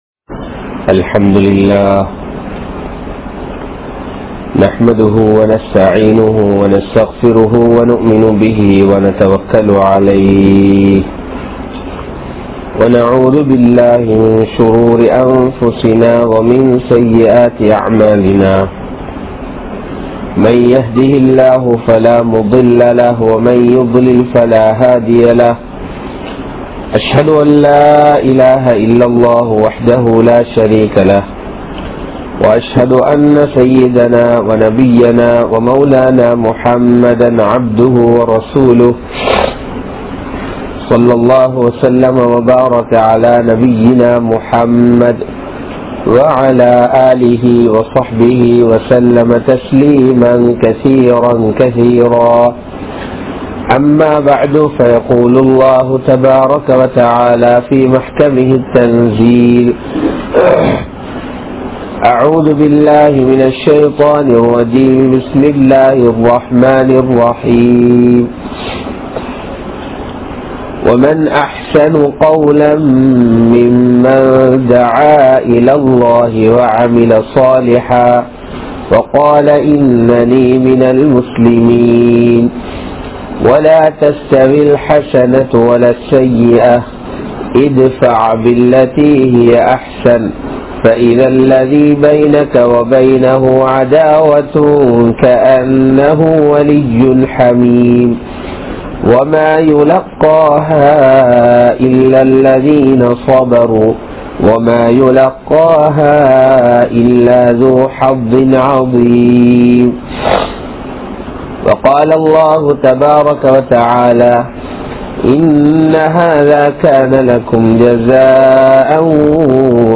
Nimmathi Ethil Undu?? (நிம்மதி எதில் உண்டு?) | Audio Bayans | All Ceylon Muslim Youth Community | Addalaichenai
Grand Jumua Masjidh